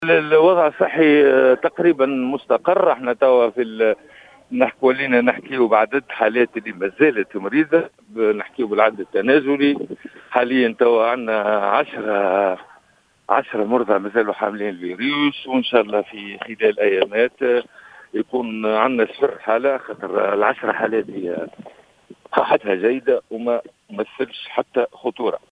قال المدير الجهوي للصحة بسوسة، سامي الرقيق في تصريح اليوم لـ"الجوهرة أف أم" إن الوضع الصحي في الجهة مستقر، حيث أنه لم يتم تسجيل أي إصابات جديدة بفيروس "كورونا" منذ 21 يوما.